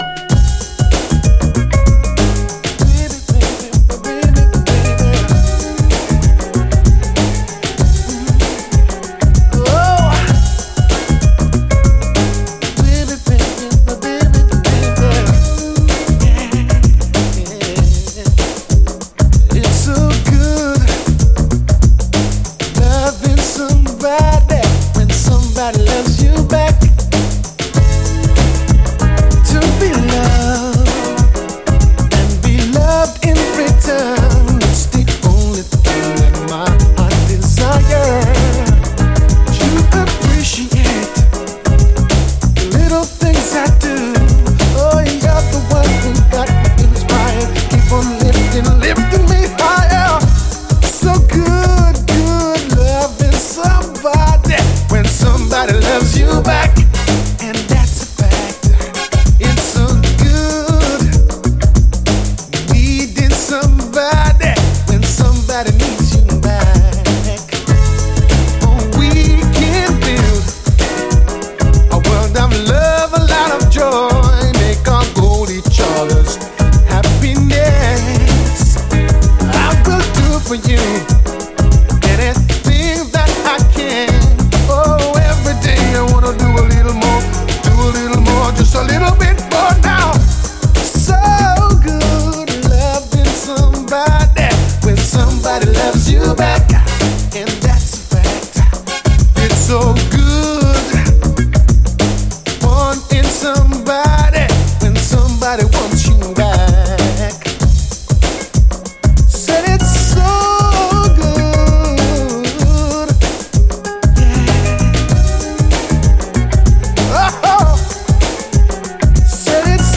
SOUL, 70's～ SOUL
直球にグラウンド・ビートでわかりやすくR&Bなアレンジです。